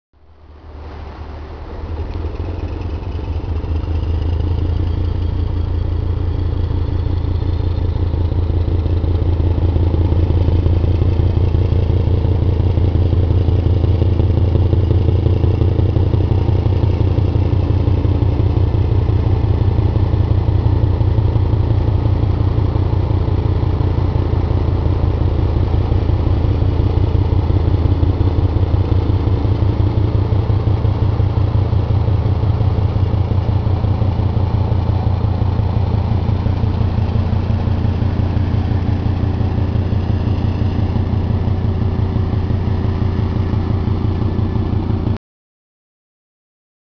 tynningo.wav